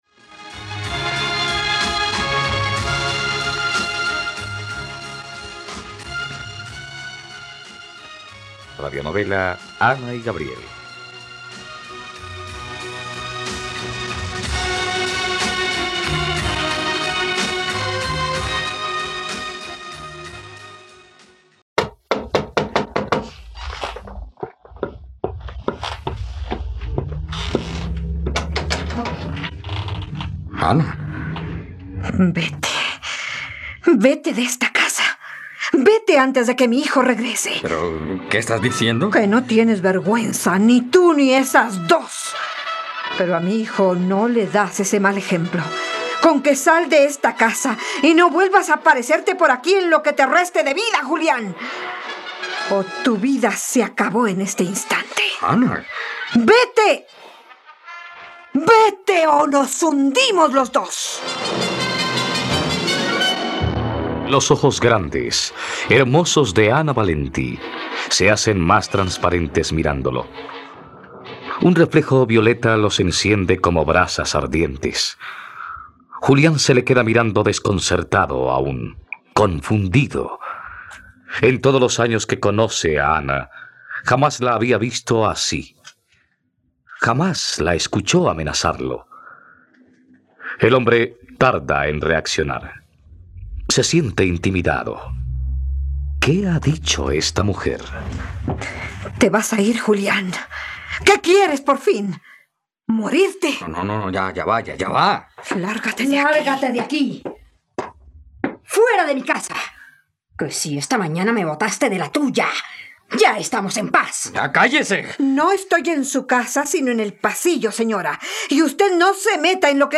Ana y Gabriel - Radionovela, capítulo 44 | RTVCPlay